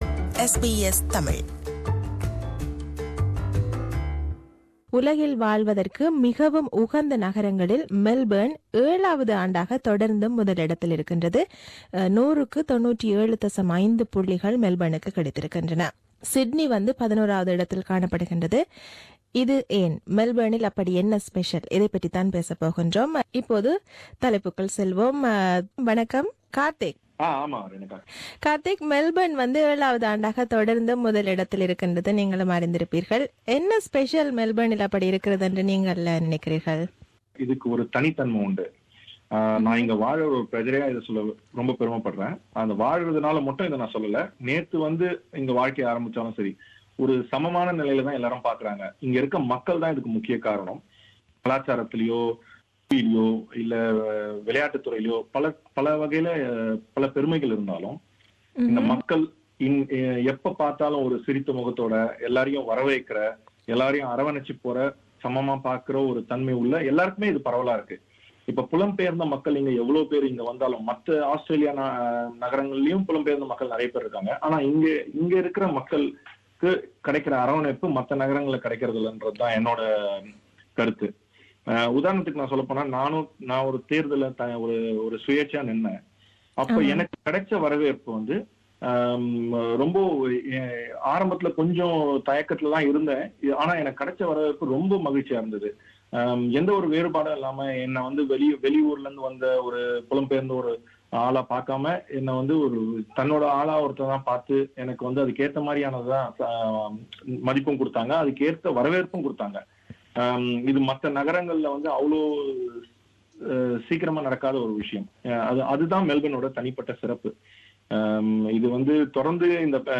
This is the compilation of opinions expressed by our listeners who participated in Talkback (Vanga Pesalam) program on 25 August 2017.